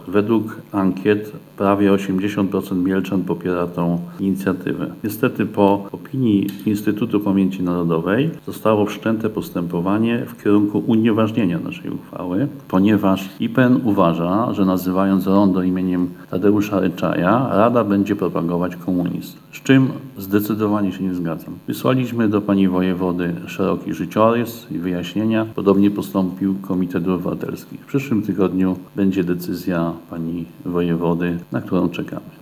Mówi przewodniczący Rady Miejskiej w Mielcu Bogdan Bieniek: